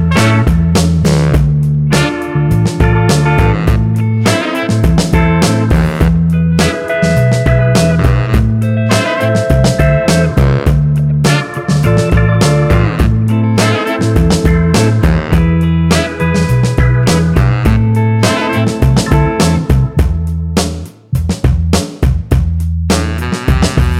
No Main Guitar Pop (2000s) 4:12 Buy £1.50